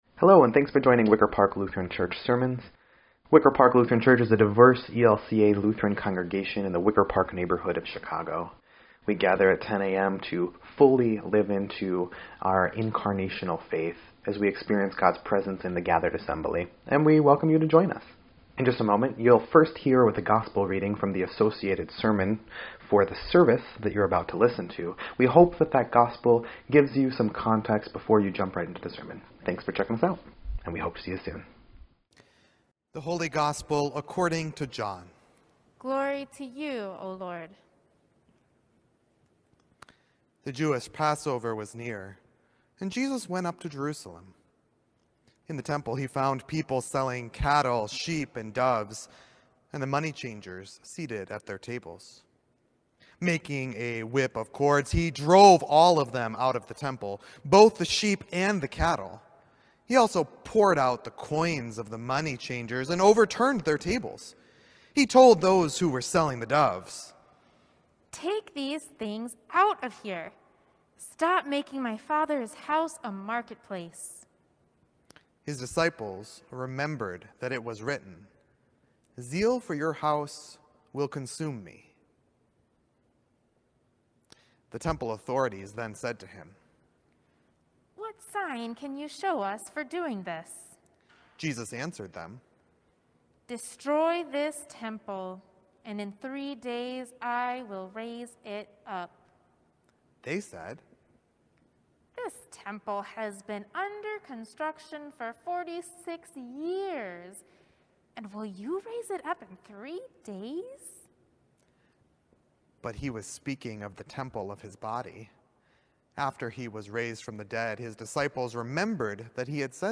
3.7.21-Sermon_EDIT.mp3